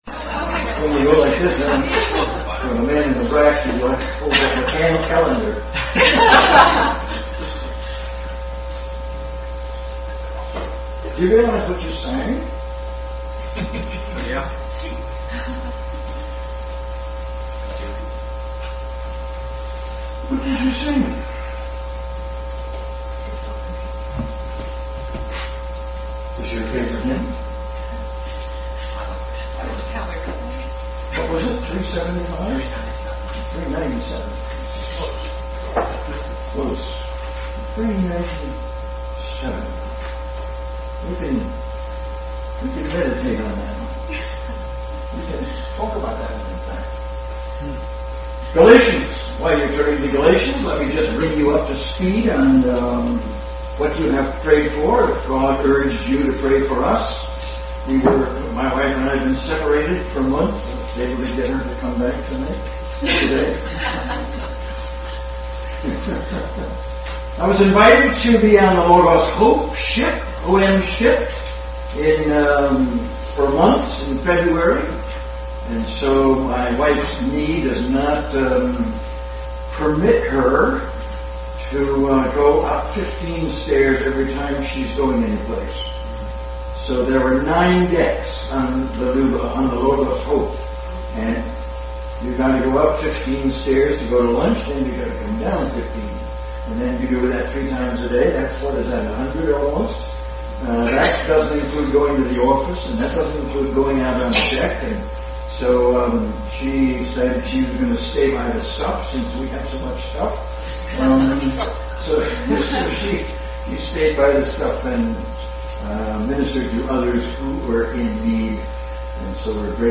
Bible Text: Galatians 1:15-16 | Family Bible Hour Message.